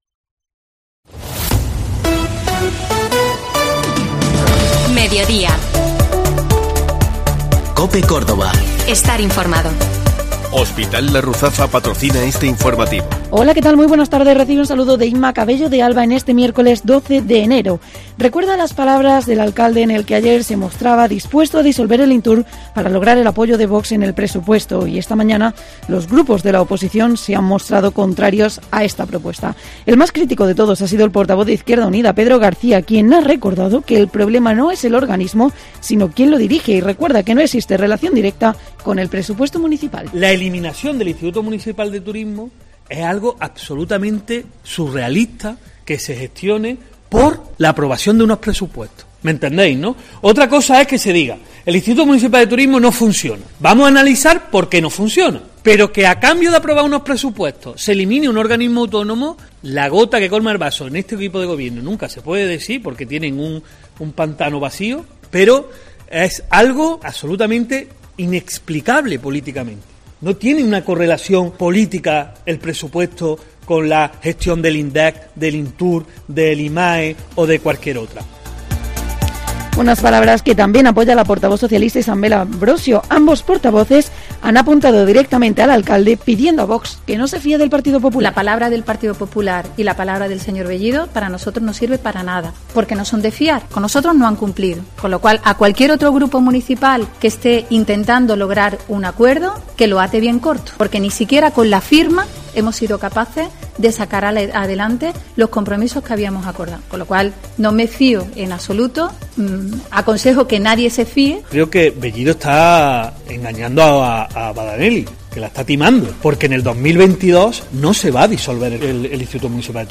LA ACTUALIDAD CADA DÍA
Escucha Mediodía COPE en Córdoba en el 87.6 FM, 1215 OM y en la App de COPE, disponible gratuitamente para iOS y Android.